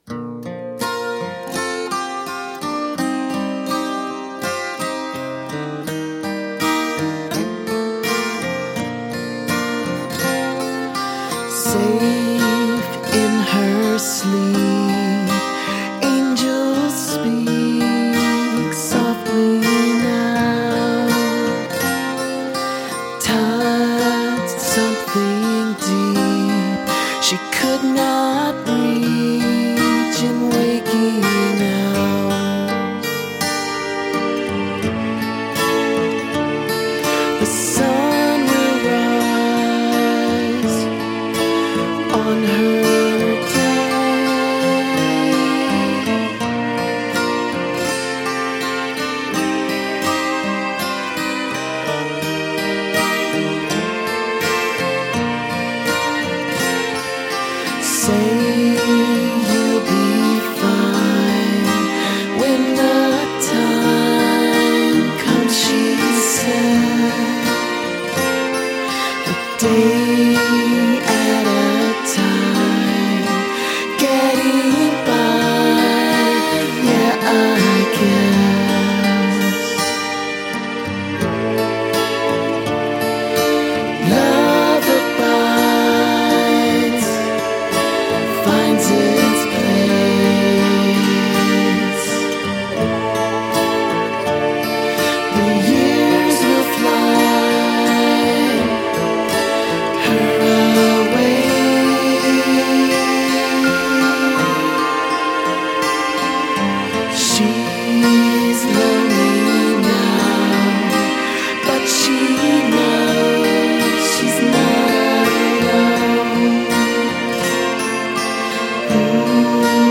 This ballad is the album’s most mellow and melodic moment.